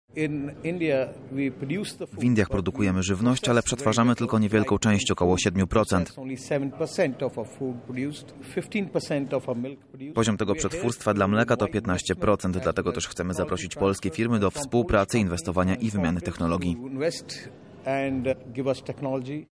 O znaczeniu współpracy między państwami mówi Sukhbir Singh Badal wicepremier rządu stanu Punjab.